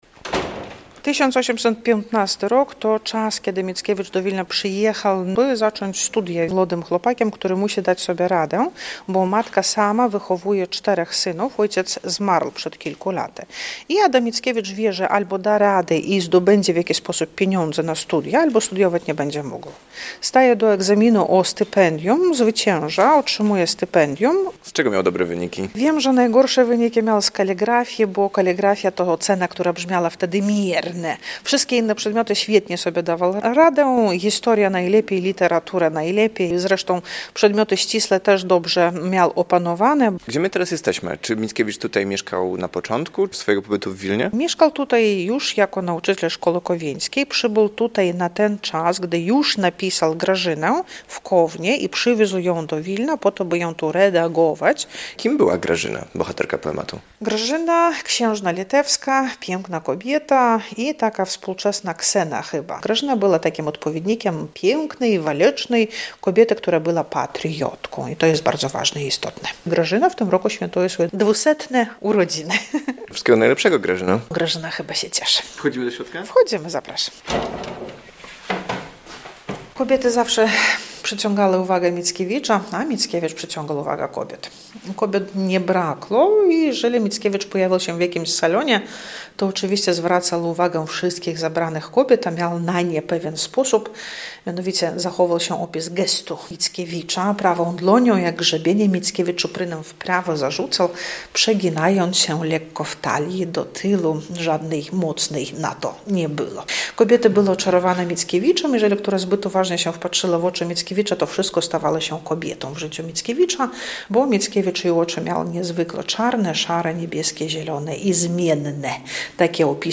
Odwiedziliśmy muzeum Adama Mickiewicza w Wilnie